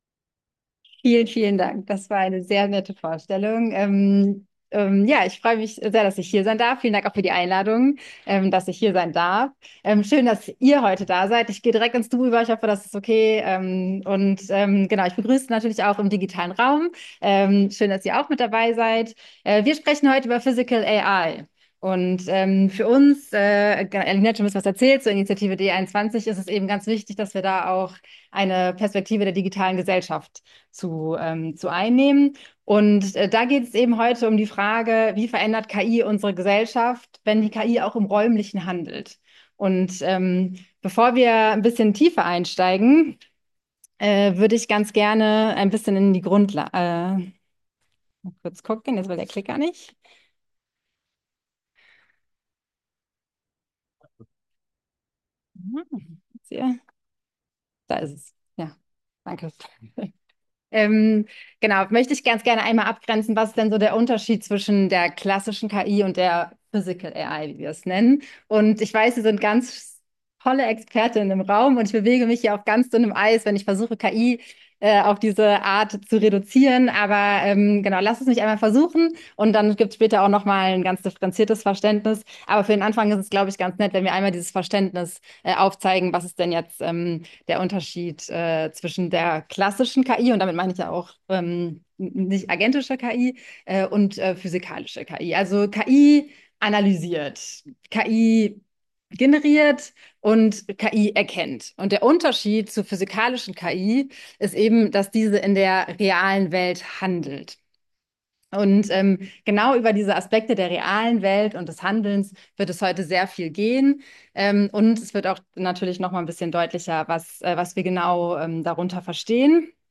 Ringvorlesung KI & Ethik_��˹ά��˹�ĳ�